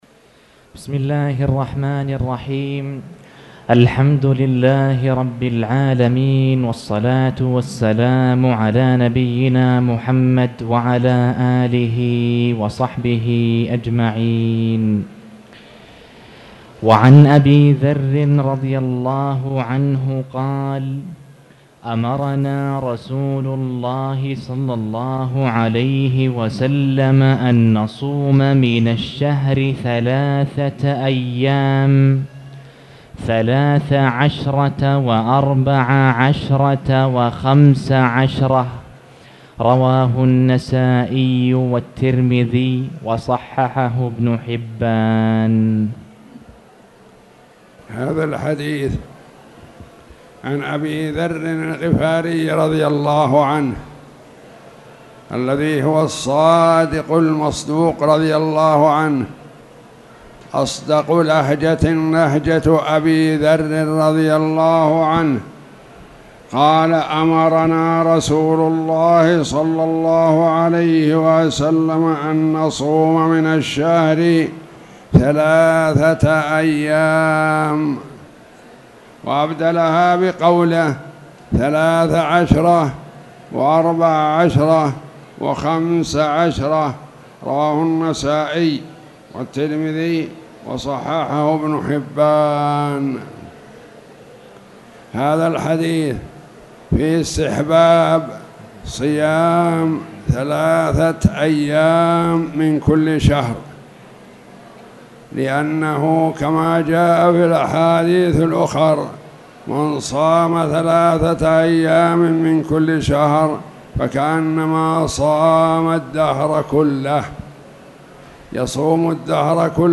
تاريخ النشر ١٨ شوال ١٤٣٧ هـ المكان: المسجد الحرام الشيخ